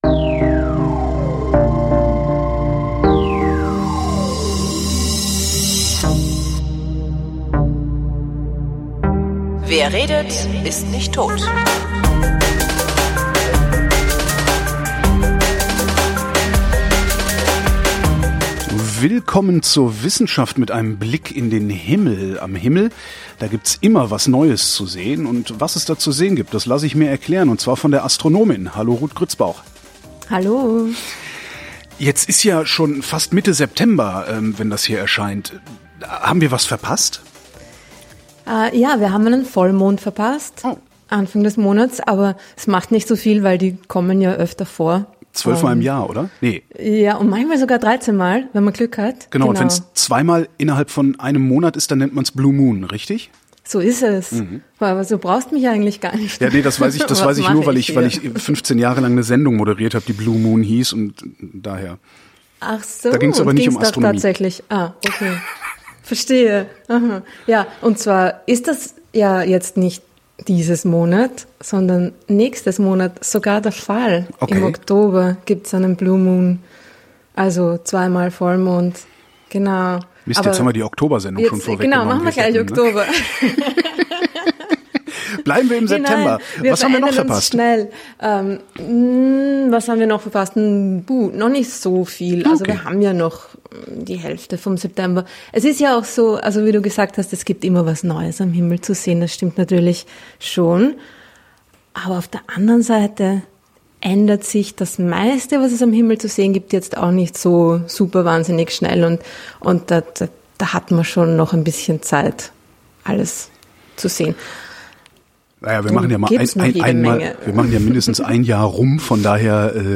Weil in jeder Sendung geredet wird, habe ich mir erlaubt, das Projekt nach einem Satz aus Gottfried Benns Gedicht “Kommt” zu benennen.